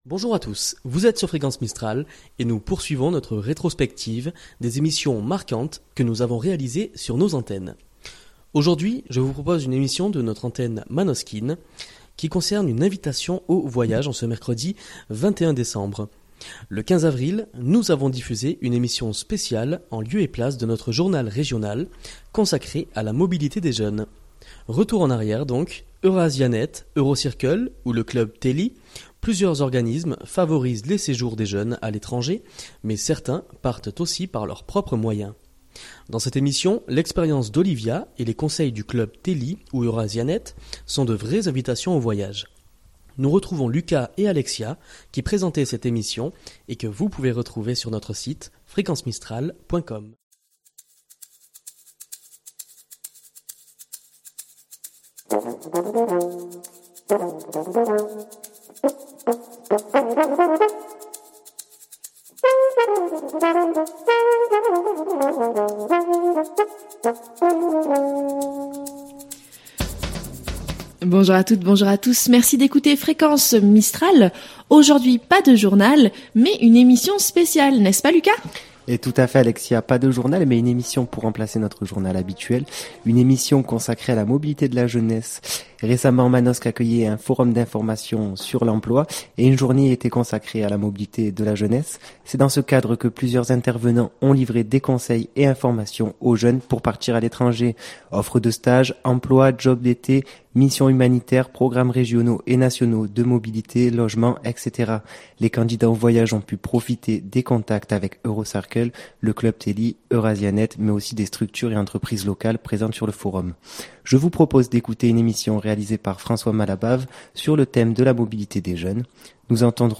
Pendant cette période de fêtes Fréquence Mistral vous propose des rediffusions d'émissions qui auront marqué l'année qui s'achève.